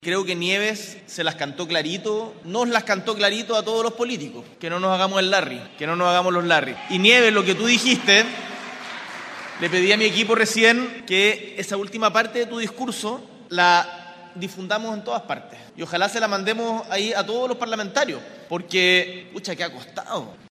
en el marco de la actividad por lo que será el Aguinaldo Navideño para pensionados